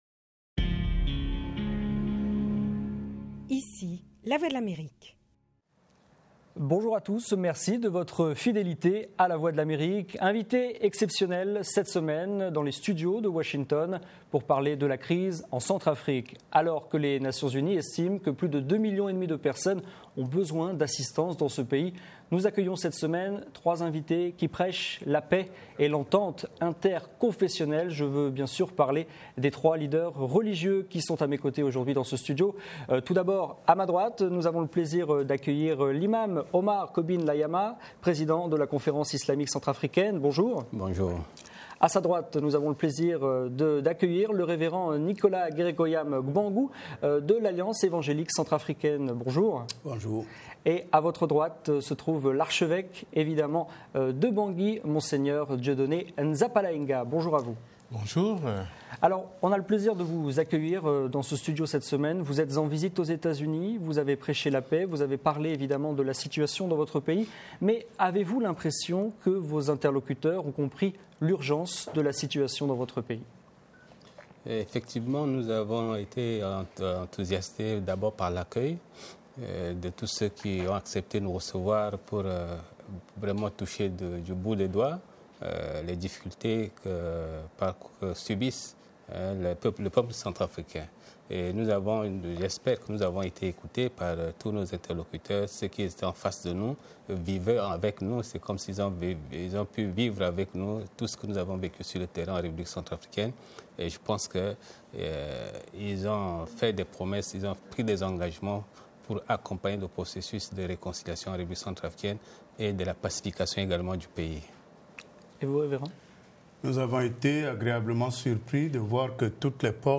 Entretien intégral avec Dieudonné Nzapalainga